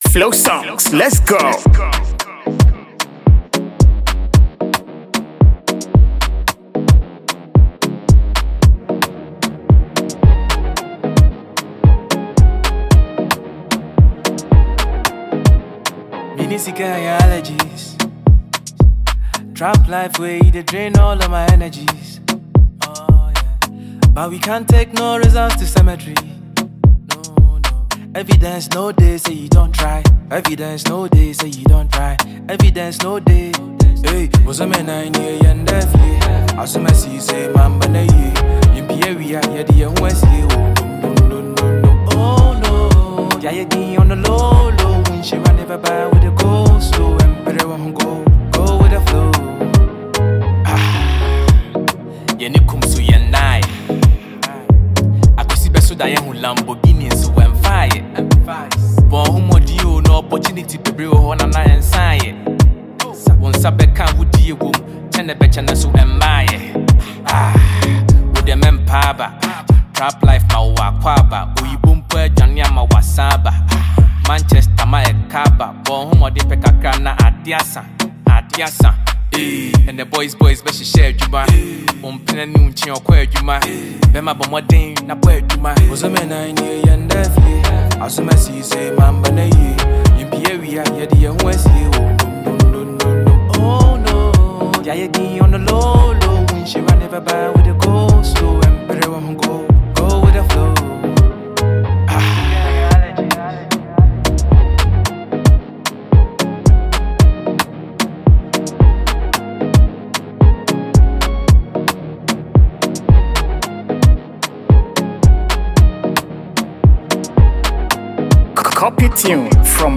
GHANAIAN singer-songwriter
This soulful track
powerful vocals and emotional delivery
With its catchy melody and heartfelt lyrics